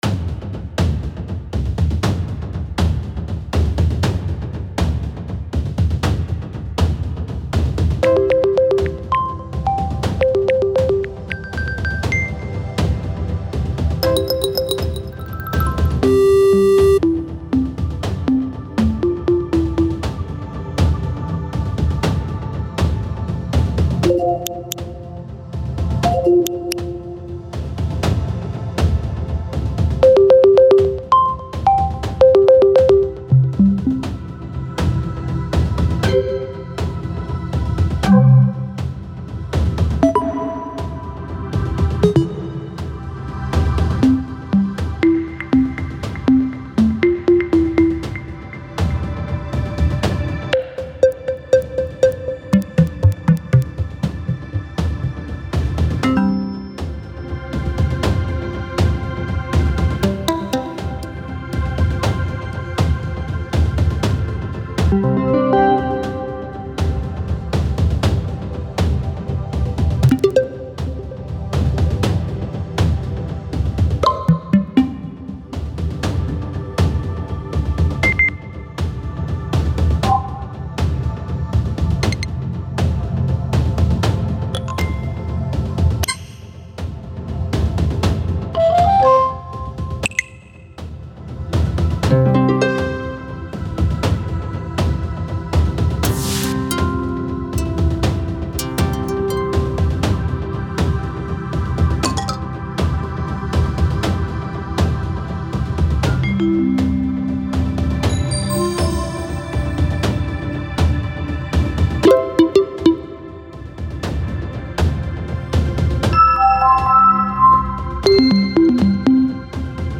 Ήχοι ειδοποίησης
100% original πρωτότυπη σύνθεση ήχων, σύντομα beeps/clicks, μαρίμπα, ή ρυθμικοί ήχοι, σχεδιασμένοι εξ αρχής με όργανα εφέ και στυλ που επιθυμείς.
UI-sounds-RT.mp3